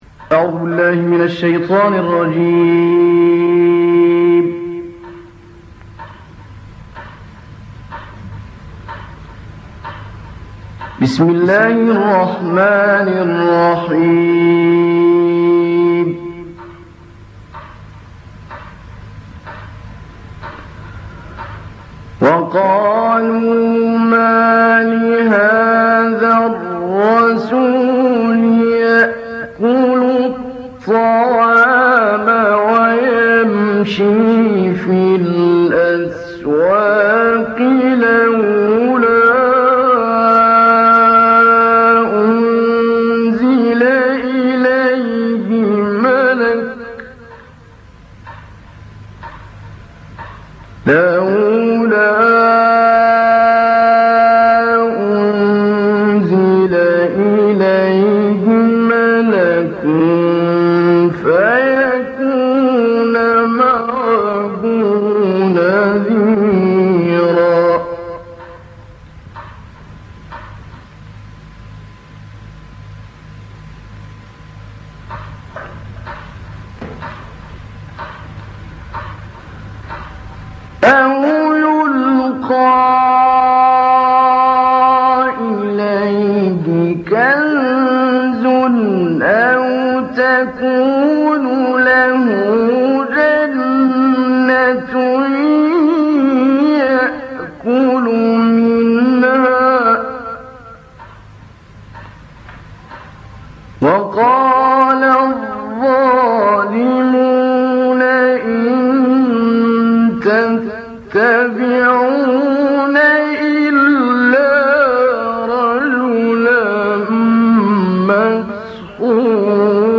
تلاوت سوره فرقان «محمود علی البناء»
گروه فعالیت‌های قرآنی: تلاوت آیاتی از سوره فرقان با صوت محمود علی البناء ارائه می‌شود.
نکته جالب این تلاوت صدای تیک تاک ساعت است که به گوش می‌رسد. آن زمان در استودیوها ساعت را به دلیل جنبه هنری برنمی‌داشتند و این تلاوت‌ها به «تلاوت با تیک تاک» معروف شده‌اند.